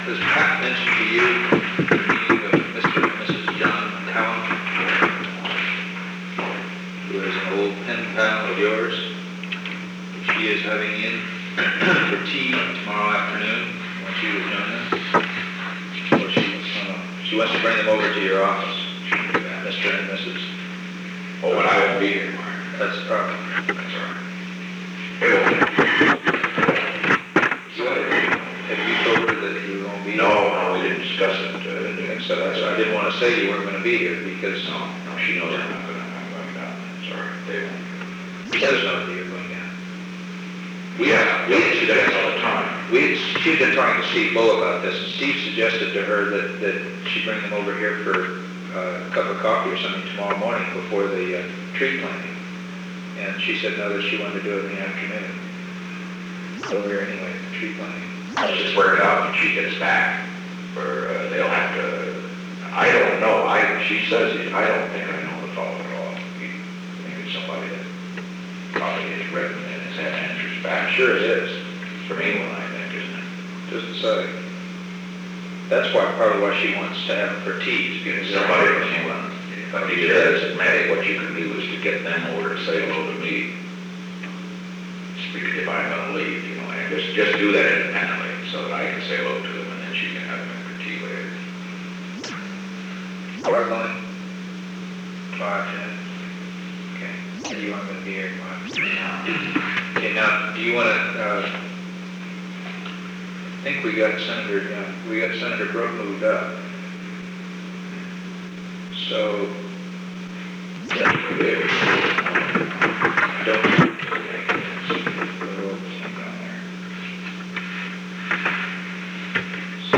Recording Device: Oval Office
The Oval Office taping system captured this recording, which is known as Conversation 498-008 of the White House Tapes.